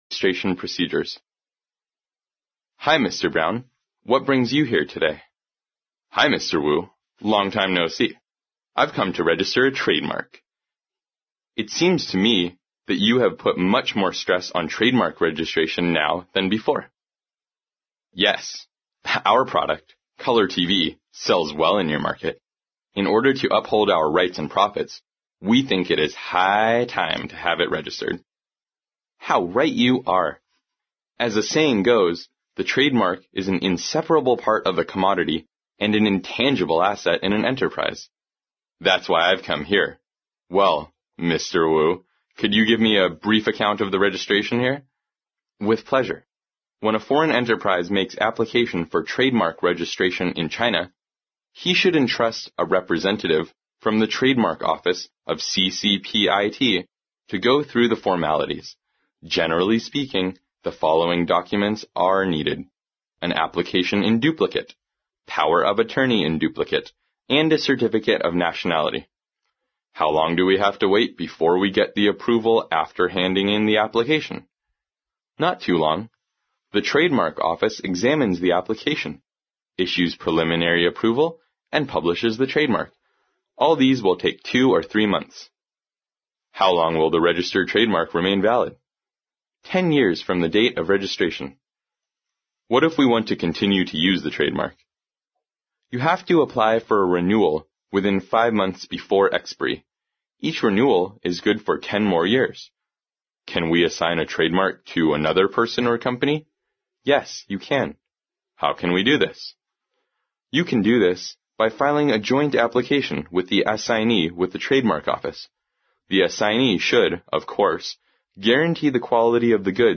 在线英语听力室外贸英语话题王 第101期:注册程序的听力文件下载,《外贸英语话题王》通过经典的英语口语对话内容，学习外贸英语知识，积累外贸英语词汇，潜移默化中培养英语语感。